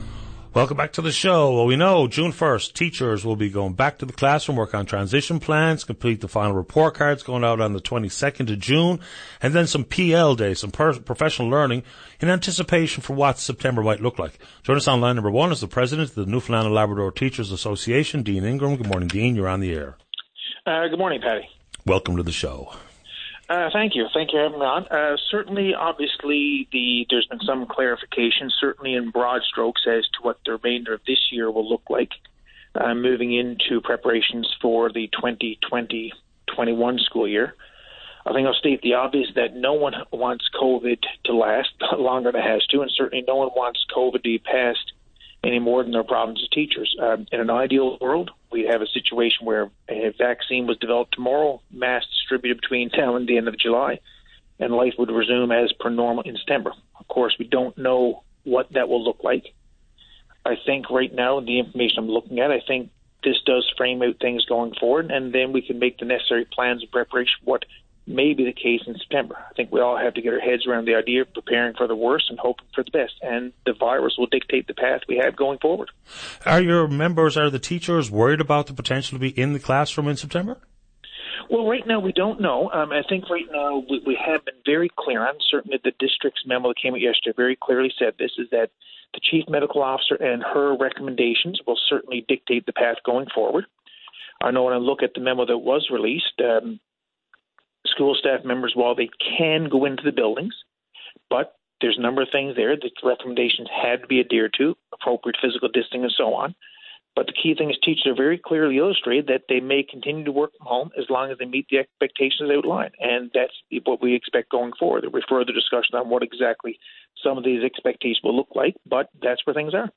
Media Interview - VOCM Open Line May 26, 2020